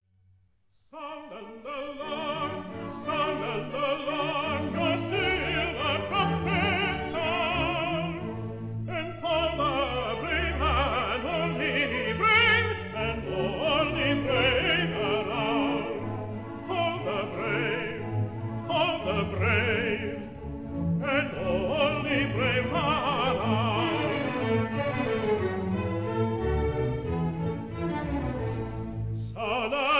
stereo recording
tenor